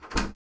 OpenBox.wav